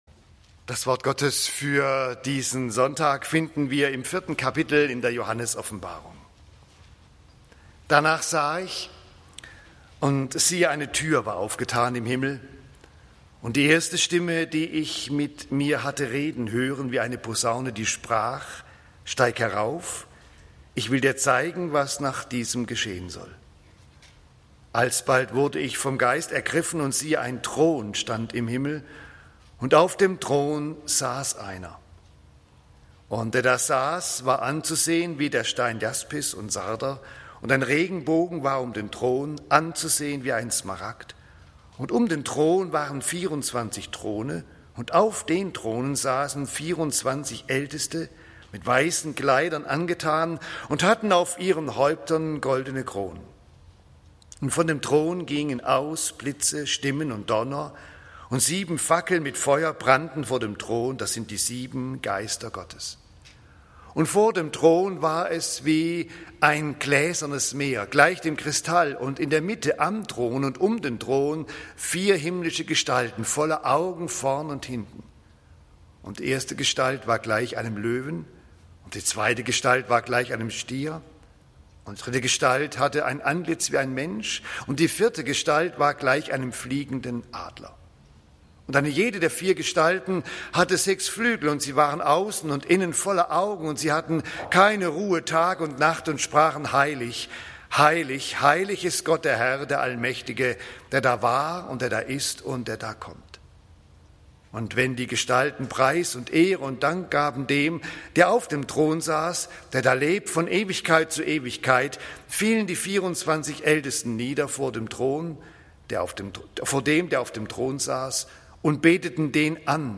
"Es wird regiert!" (Offb. 4+5) - Gottesdienst